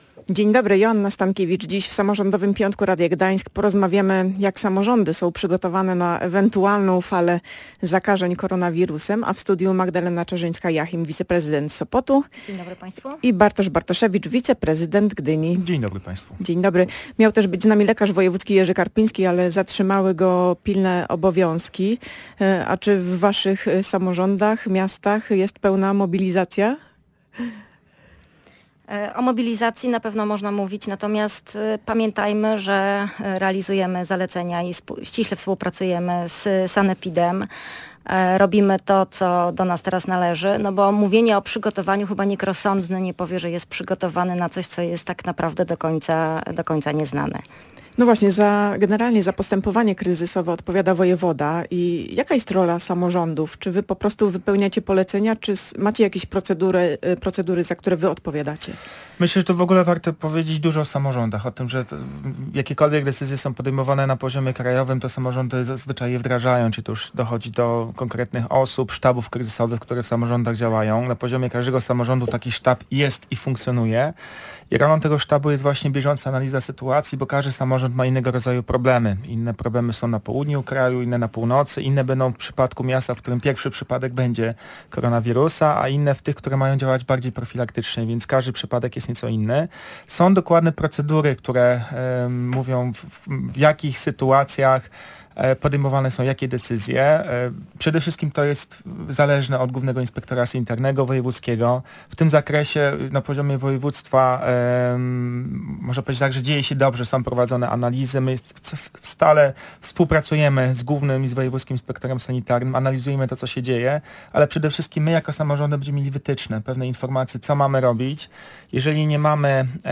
Czy samorządy są przygotowane na ewentualną falę zakażeń koronawirusem? Czy są procedury w tej kwestii, za które odpowiada samorząd? Jak wyglądają kampanie informacyjne? Między innymi na te pytania odpowiadali goście audycji Samorządowy Piątek Radia Gdańsk: Magdalena Czarzyńska-Jachim, wiceprezydent Sopotu i Bartosz Bartoszewicz, wiceprezydent Gdyni.